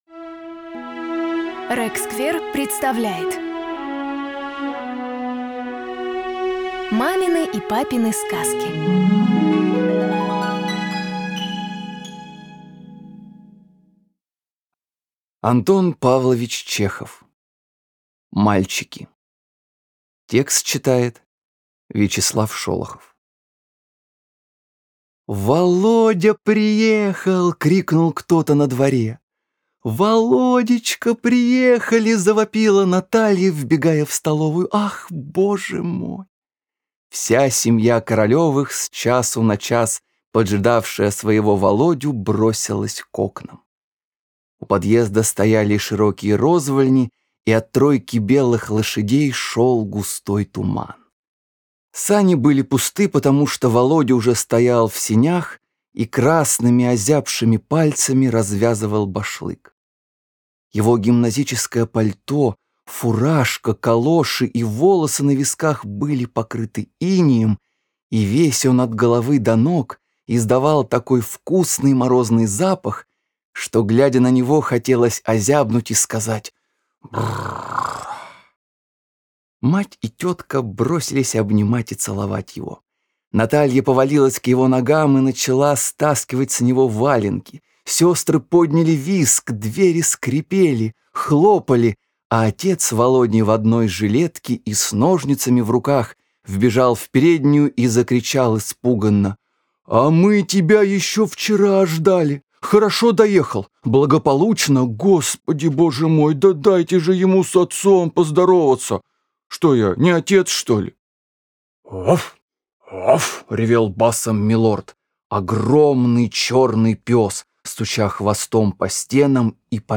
Аудиорассказ «Мальчики».